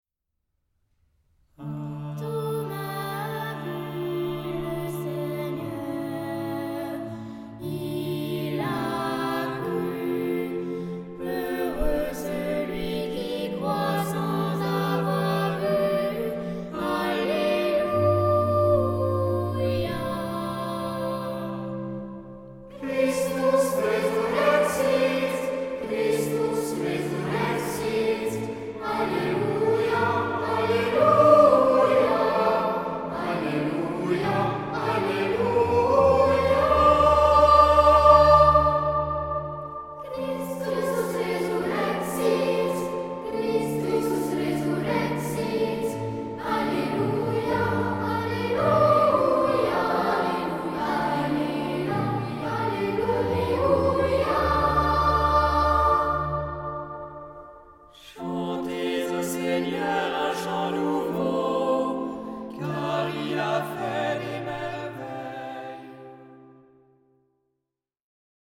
Genre-Style-Forme : Tropaire ; Psalmodie ; Sacré
Caractère de la pièce : recueilli
Instruments : Orgue (1) ; Instrument mélodique (ad lib)
Tonalité : ré mineur ; fa majeur